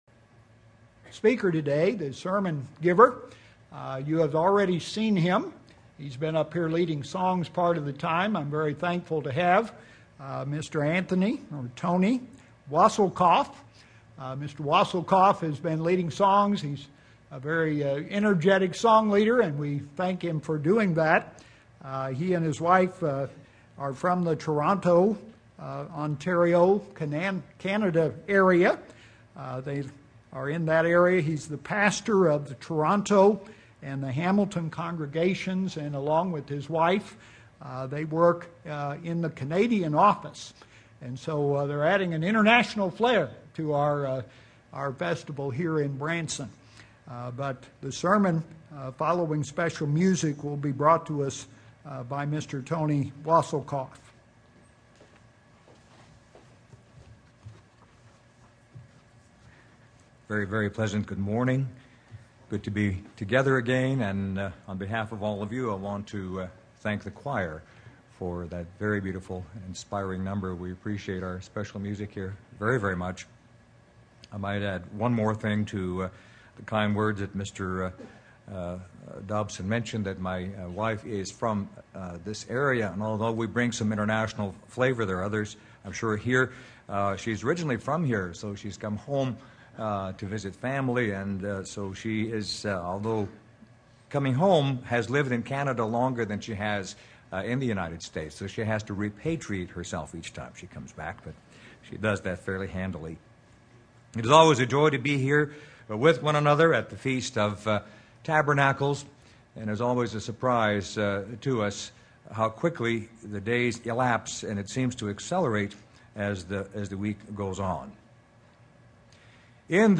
This sermon was given at the Branson, Missouri 2012 Feast site.